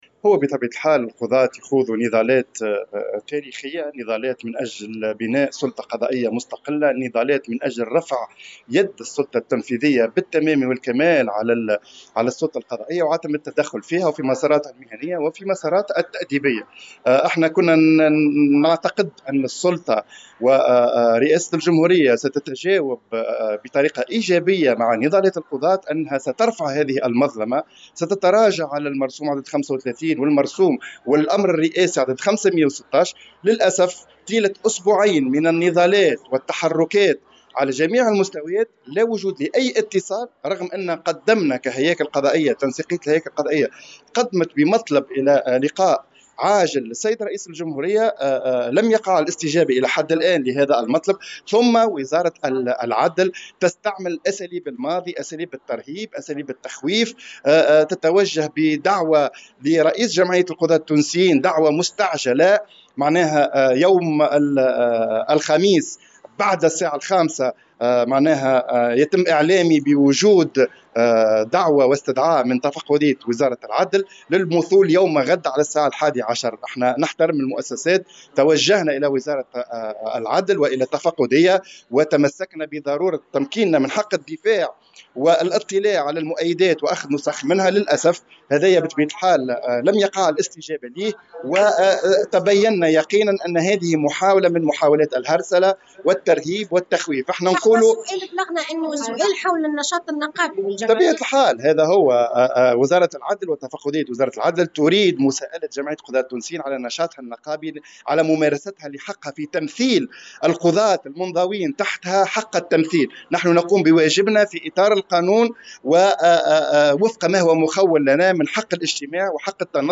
في تصريح إعلامي على هامش جلسة عامة لتنسيقية الهياكل القضائية منعقدة صباح اليوم السبت